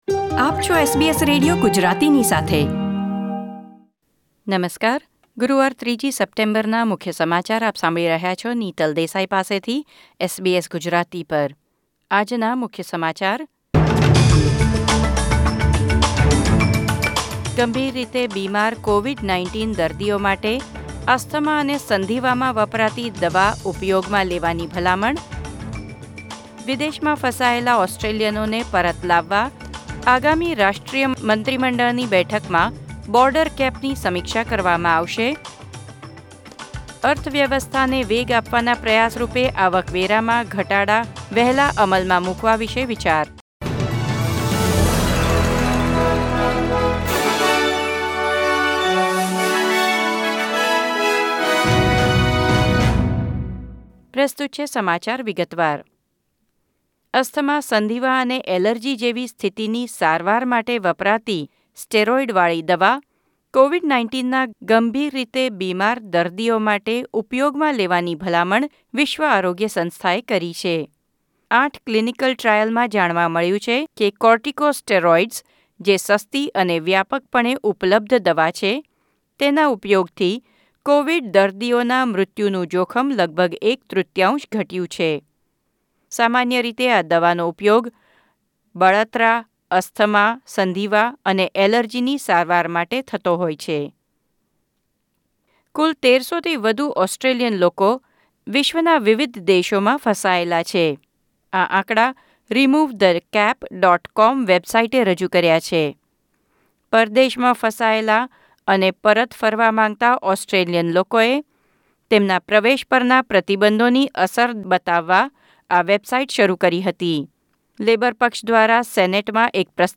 SBS Gujarati News Bulletin 3 September 2020